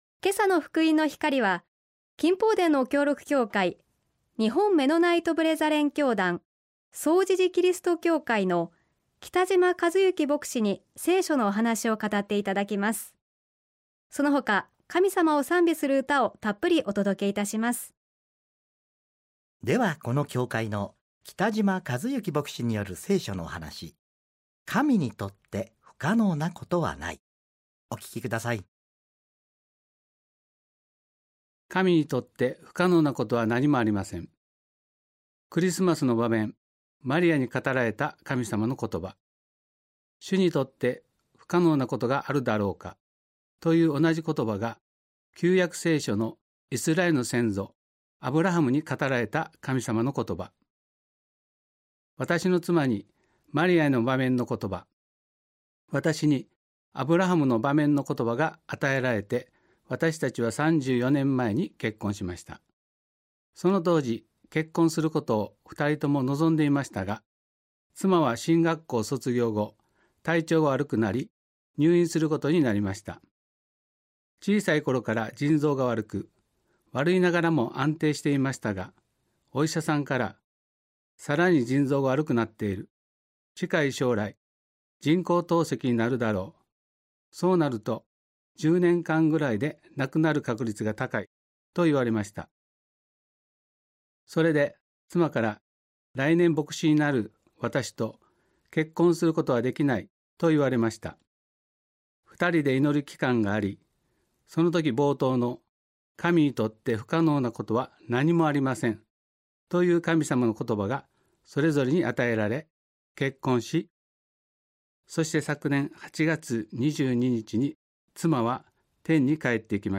聖書のお話「神にとって不可能なことはない」